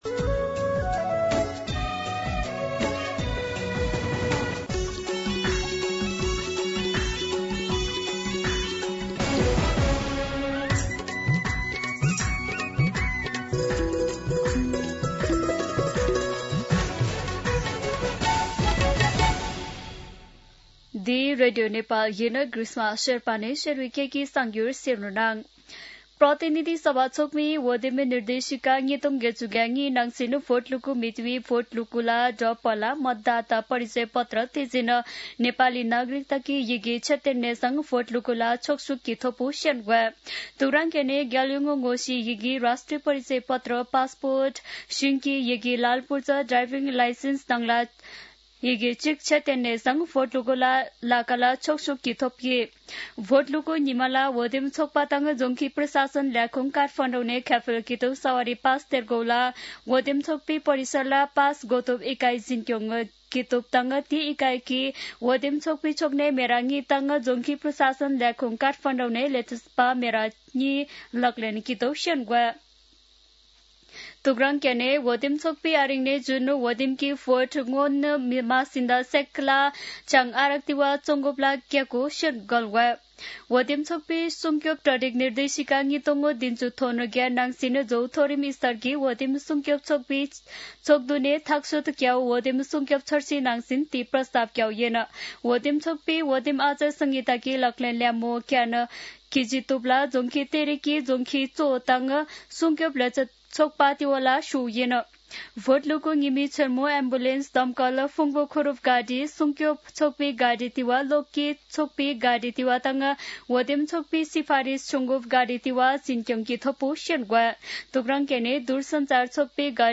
शेर्पा भाषाको समाचार : १५ फागुन , २०८२
Sherpa-News-15.mp3